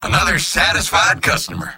Robot-filtered lines from MvM. This is an audio clip from the game Team Fortress 2 .
{{AudioTF2}} Category:Engineer Robot audio responses You cannot overwrite this file.